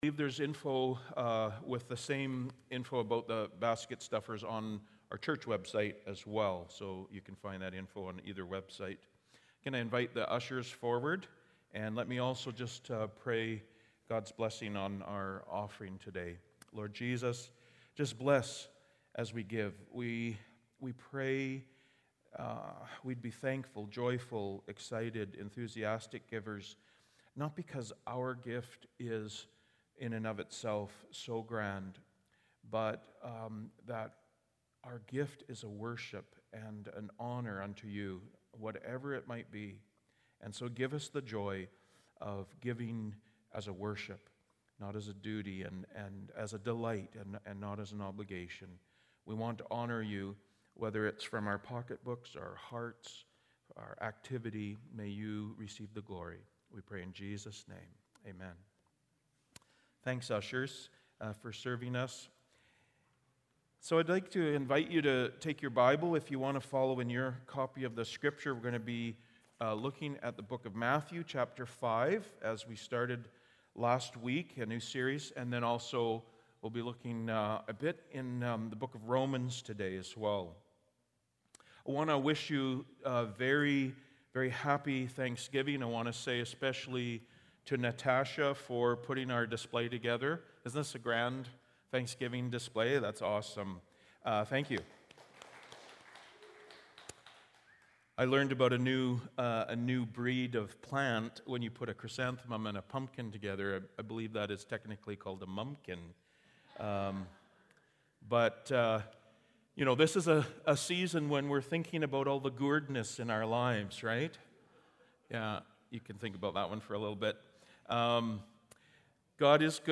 Sermons | Cornerstone Neighbourhood Church